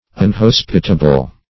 Unhospitable \Un*hos"pi*ta*ble\, a.